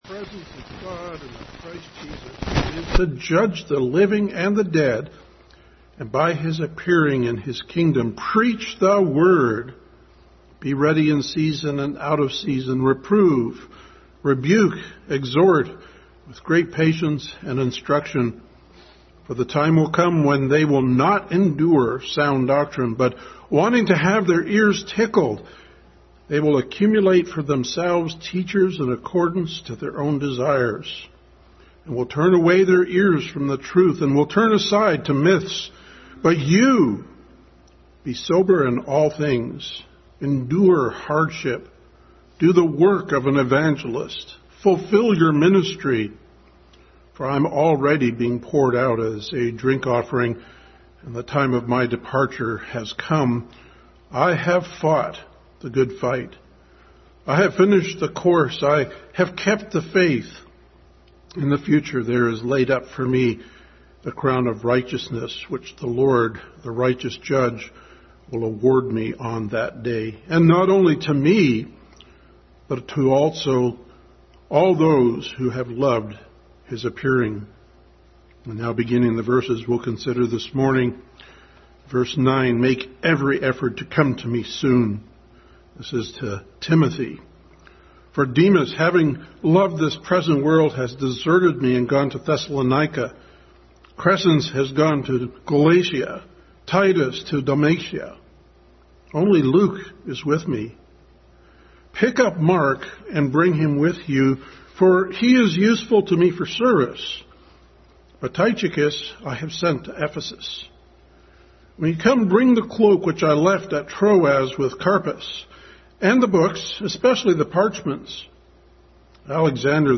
Passage: Ephesians 2:1-10 Service Type: Family Bible Hour